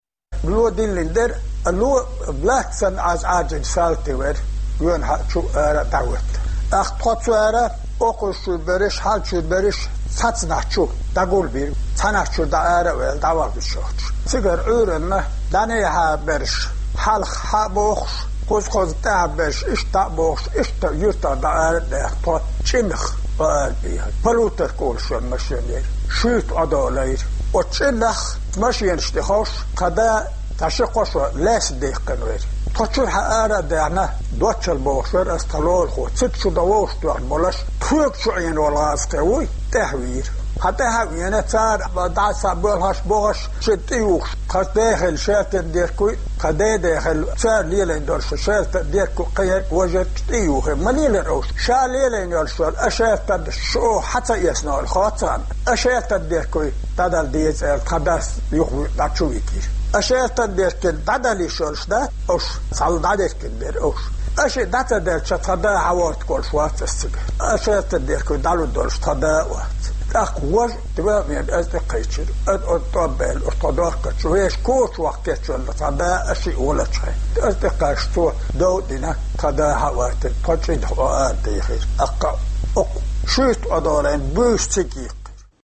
Ломан юьртарчу шайн чохь дууш-молуш баьхна, шайн дайн духар, куйнаш лелош, шайца тIекаре лелла НКВД-н салтий махках шаьш дохуш шайца мел къиза бара, тешнабехк цара муха бира, дийцира Маршо Радиога кху воккхачу стага.